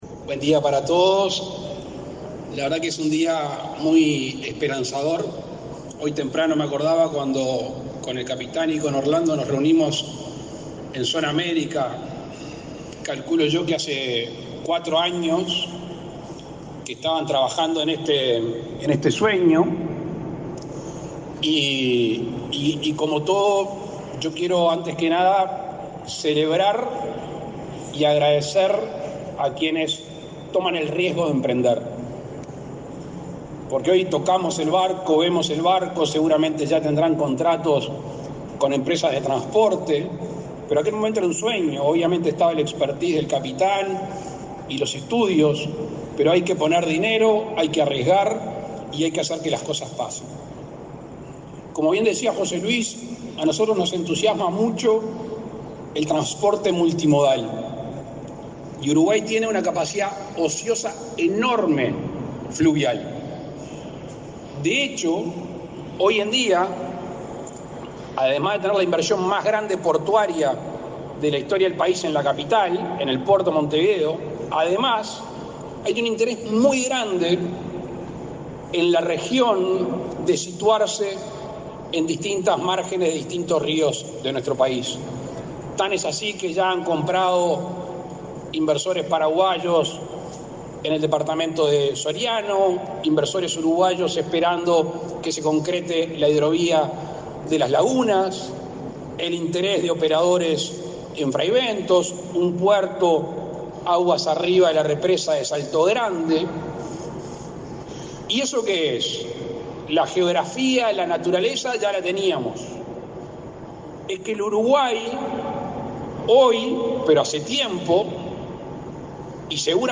Palabras del presidente de la República, Luis Lacalle Pou
El presidente Luis Lacalle Pou participó, este 28 de mayo, en la presentación de la compañía fluvial Línea del Plata y el bautismo de buque Expreso